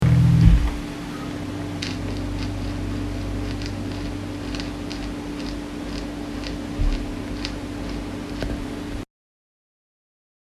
Pickup fiept und knistert
Mir ist heute zum ersten Mal aufgefallen, dass mein Pick-Up fiept wenn man auf die Metallteile draufdrückt (Soundbeispiel Fiepen). Außerdem knistern beide Pickups, wenn man über die metallenen Stellen leicht geht (Soundbeispiel Knistern).